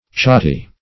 Search Result for " chati" : The Collaborative International Dictionary of English v.0.48: Chati \Cha`ti"\, n. [Cf. F. chat cat.]